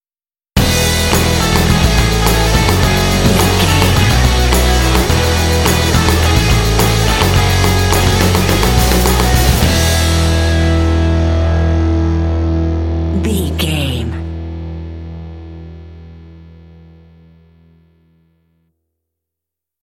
This rock track is great for action and sports games.
Epic / Action
Uplifting
Ionian/Major
driving
motivational
determined
energetic
lively
electric guitar
bass guitar
drums
alternative rock
indie